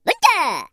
Звуки улитки
Звук голоса прыгающей улитки